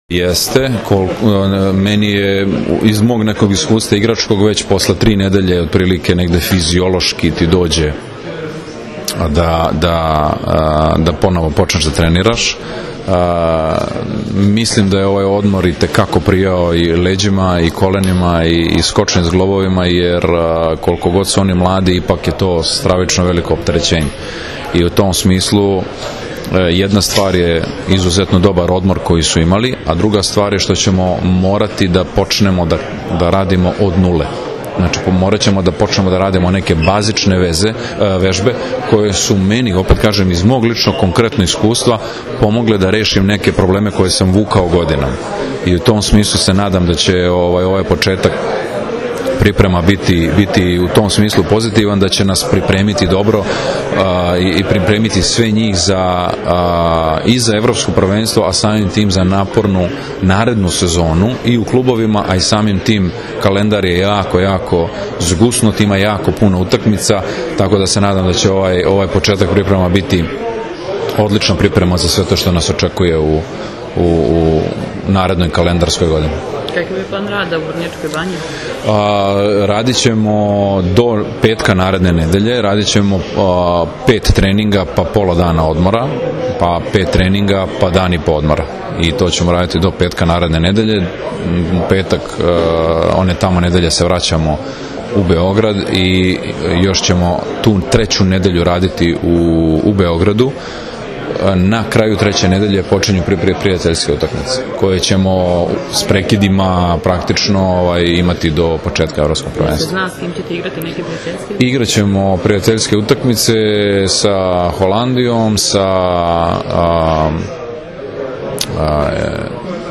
IZJAVA NIKOLE GRBIĆA, SELEKTORA SRBIJE